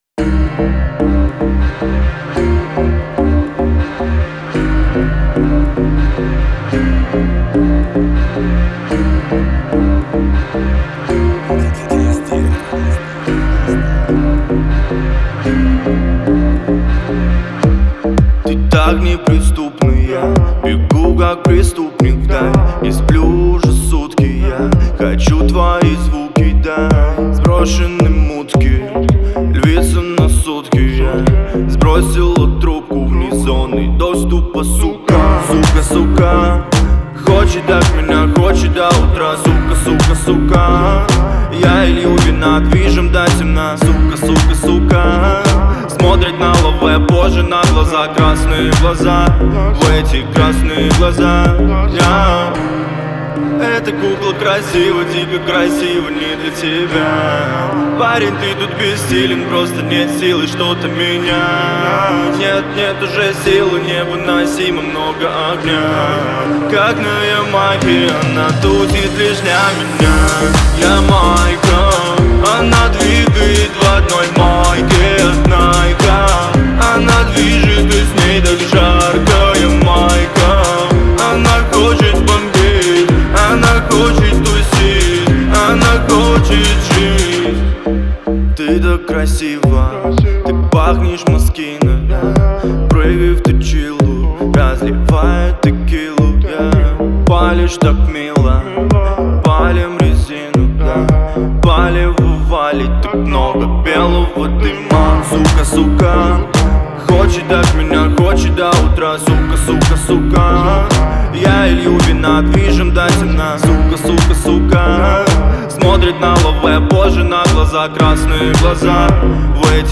это яркий трек в жанре регги